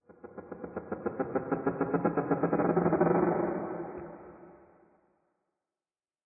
Minecraft Version Minecraft Version latest Latest Release | Latest Snapshot latest / assets / minecraft / sounds / ambient / nether / crimson_forest / twang1.ogg Compare With Compare With Latest Release | Latest Snapshot
twang1.ogg